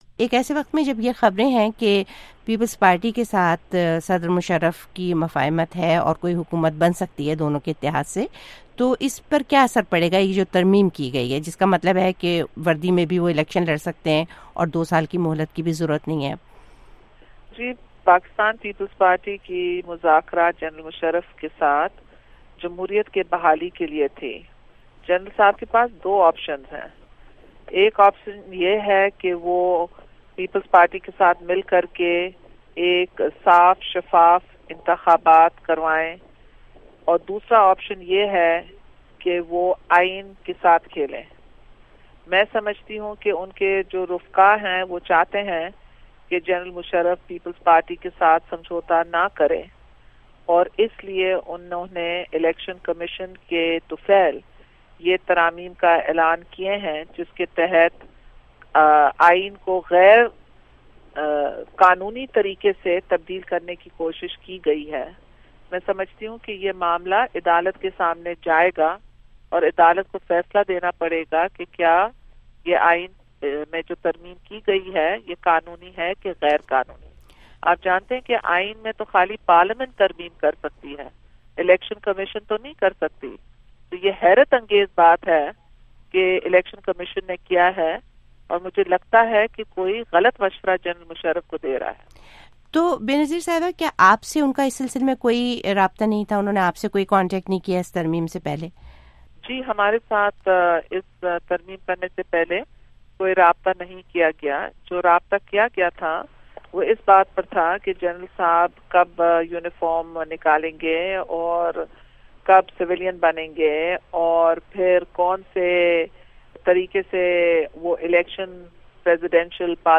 Benazir Bhutto Interview